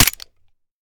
weap_mike_fire_plr_mech_last_02.ogg